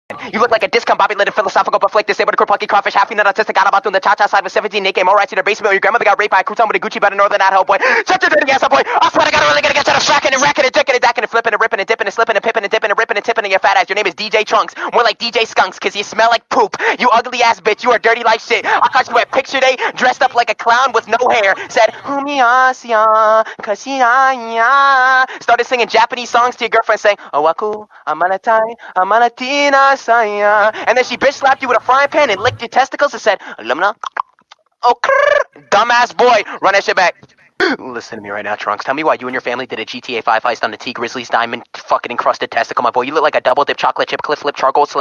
Packgod Roast Rap